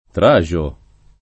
Traxo [lig. tr #X o ] → Traso